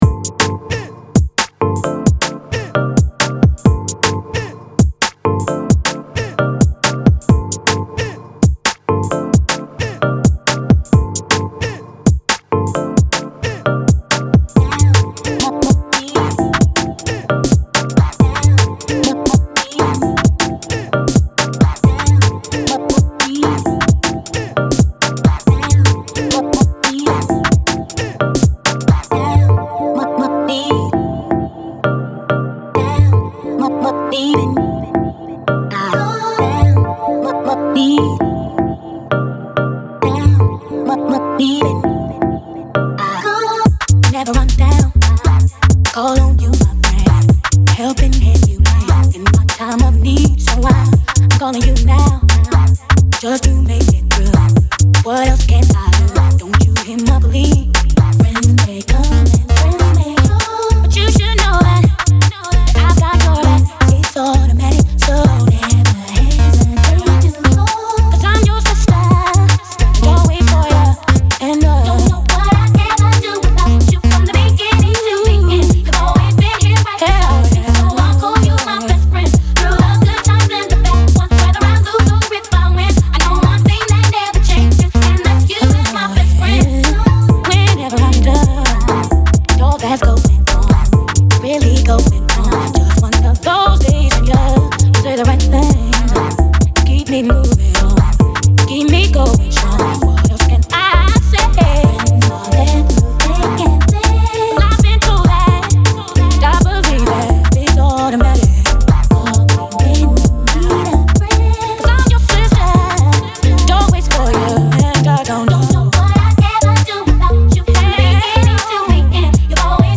free VIP rework
Bassline/Garage track